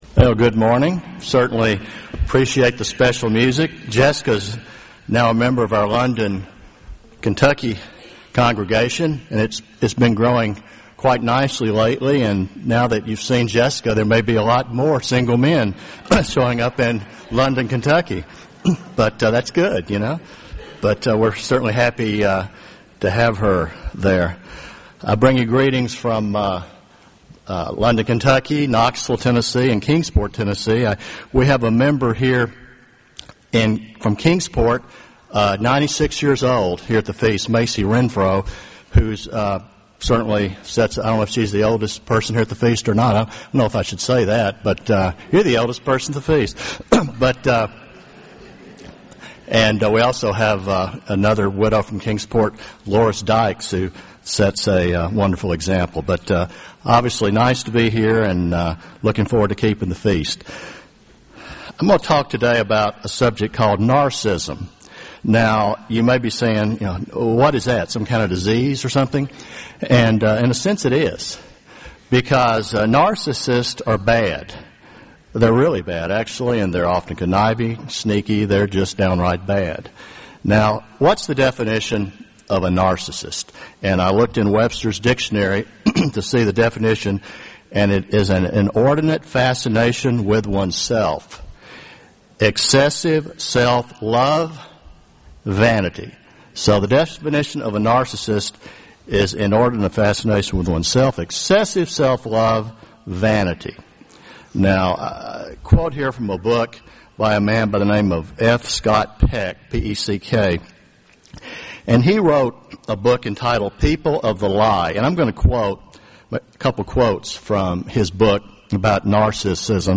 This sermon was given at the Jekyll Island, Georgia 2013 Feast site.